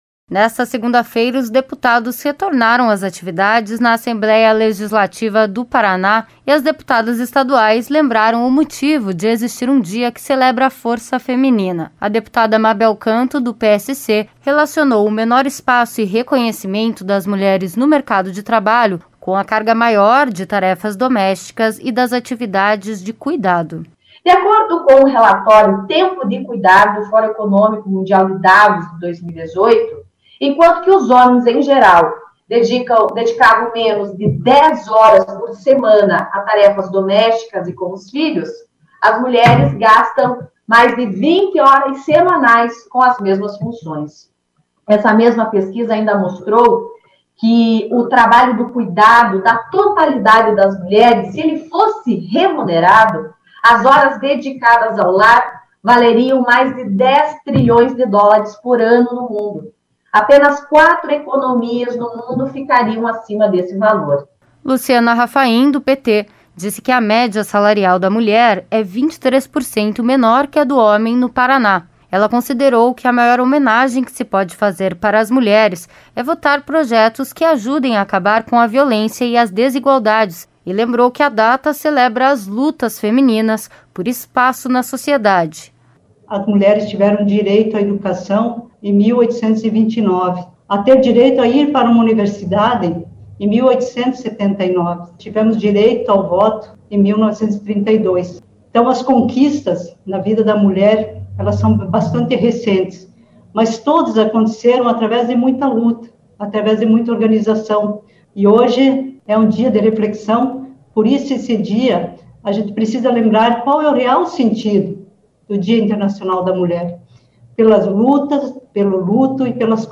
Neste dia 8 de março as deputadas estaduais falaram sobre os desafios e conquistas das mulheres paranaenses na sessão plenária e o que esperar para o futuro.